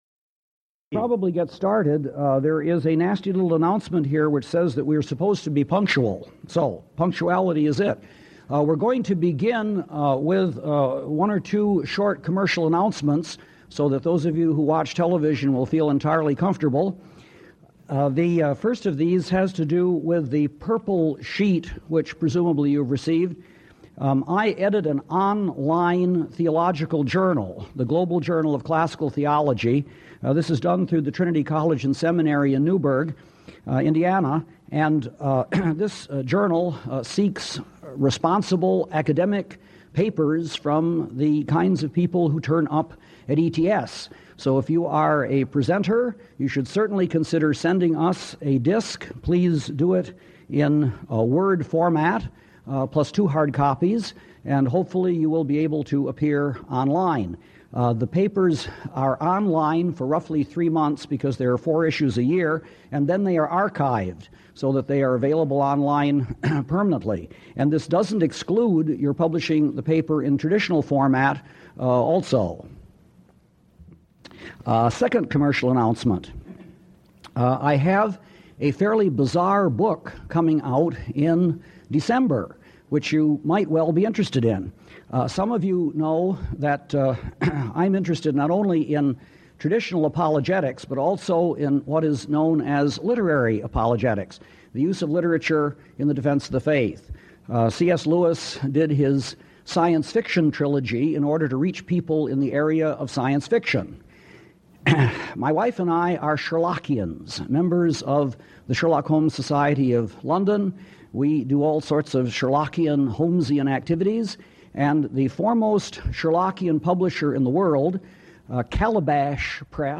Israel And Human Rights Audiobook